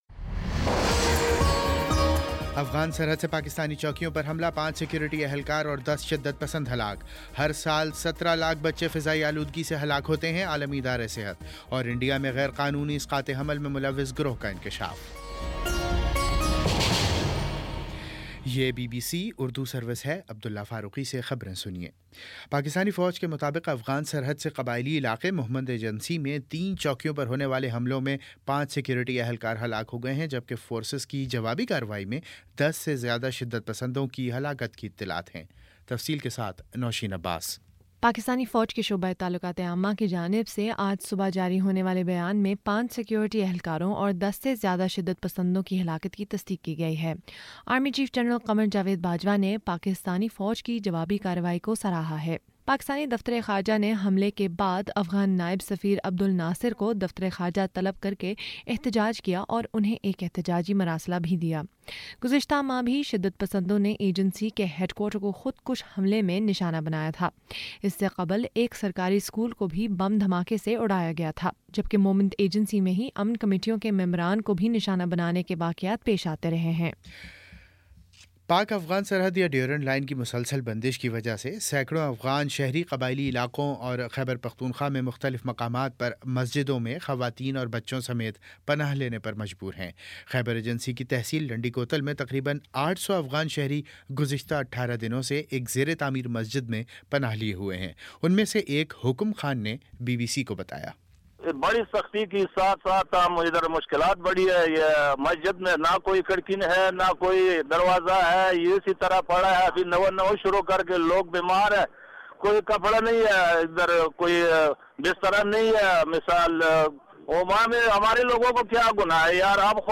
مارچ 06 : شام چھ بجے کا نیوز بُلیٹن